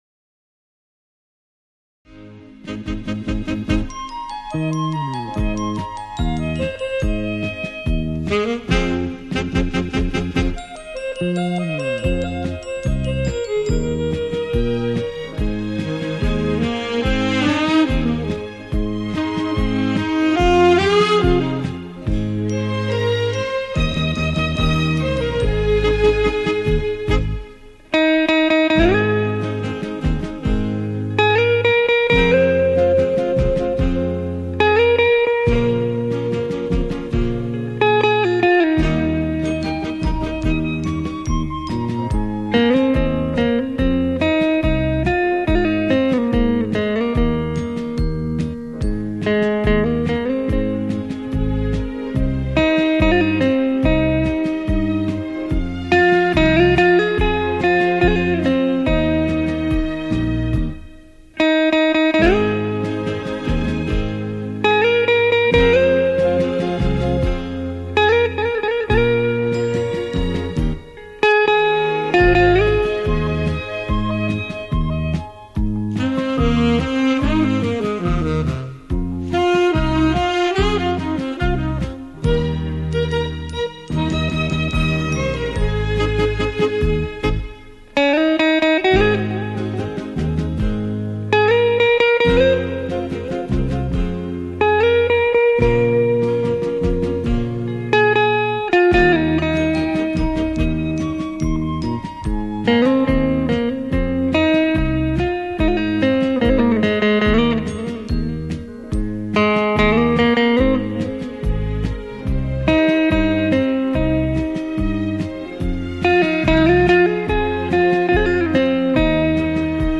電吉他